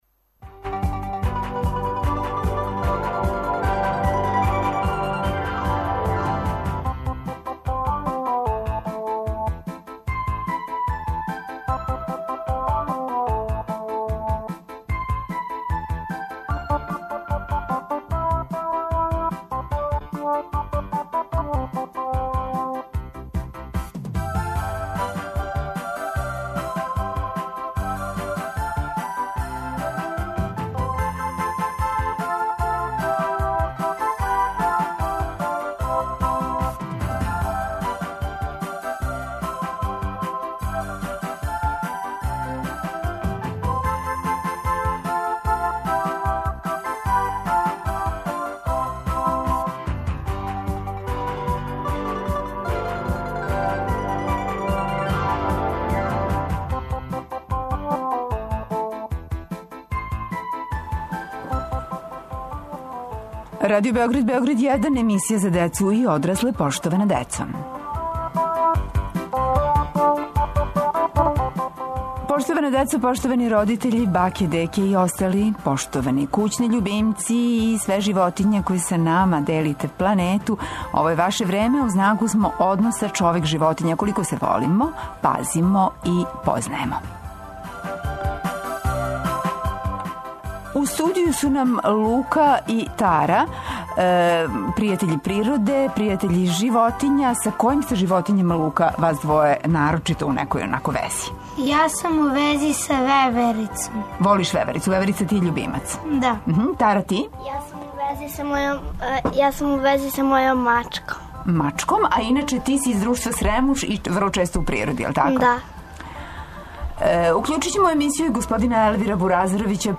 Гости емисије су деца, пријатељи животиња, и њихови родитељи.